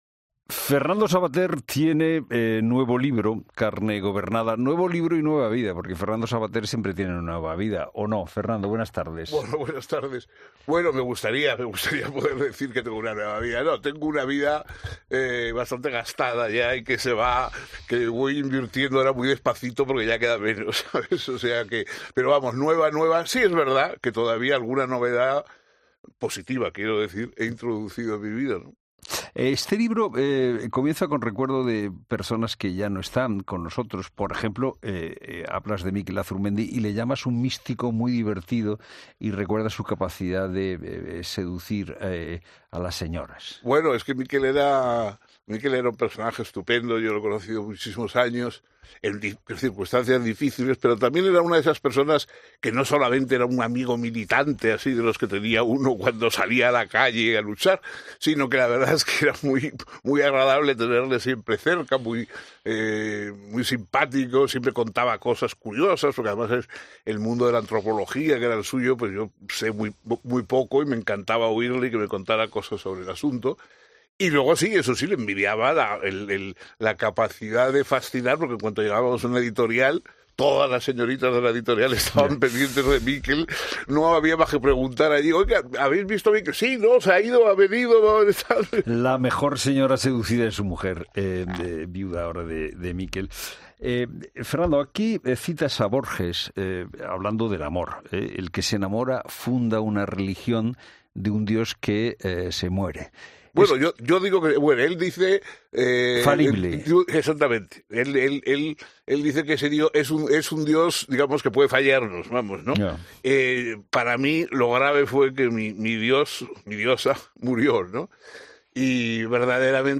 Para analizar esta nueva obra, se pasado por los micrófonos de ' La Tarde de COPE'.